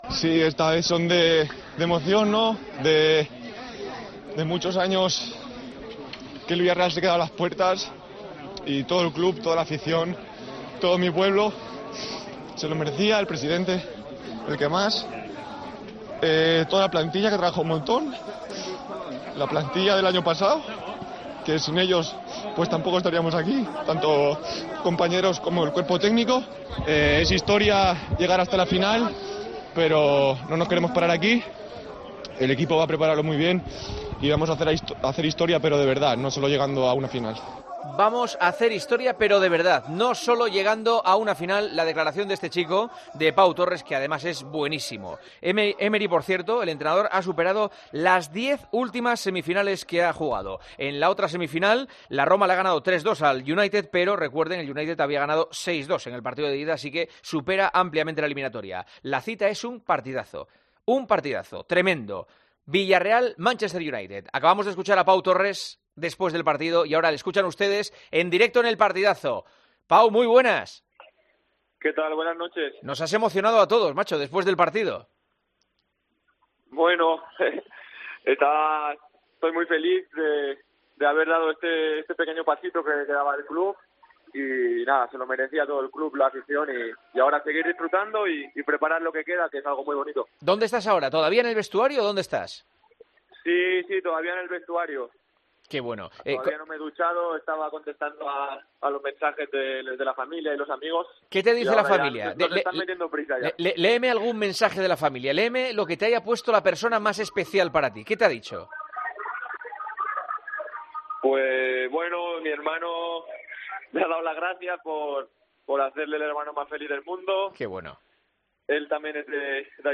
El central vila-realense, Pau Torres, celebraba este momento en el micrófono de El Partidazo de COPE.: "Es historia llegar hasta la final, pero no nos queremos parar aquí" por lo que señalaba que "el equipo va a prepararlo muy bien" y ha asegurado que "vamos a hacer historia pero de verdad, no solo llegando a una final".